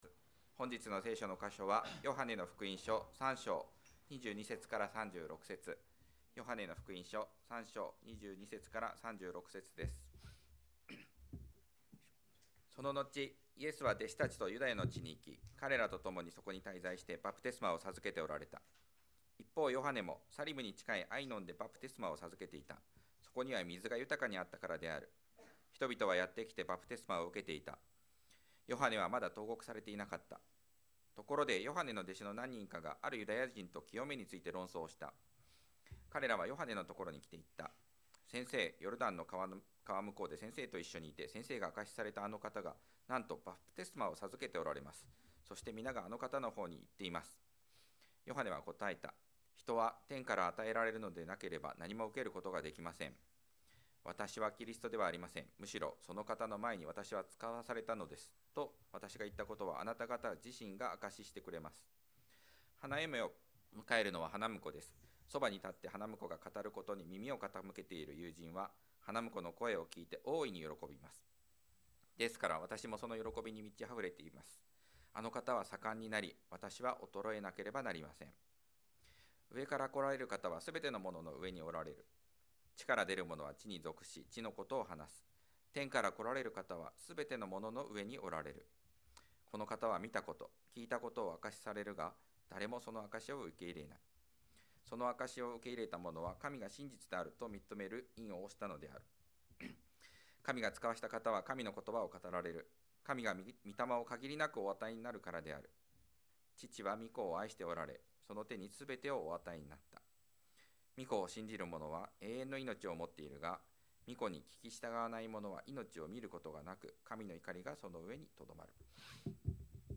2024年12月15日礼拝 説教 「あの方は盛んになり、私は衰えなければなりません」 – 海浜幕張めぐみ教会 – Kaihin Makuhari Grace Church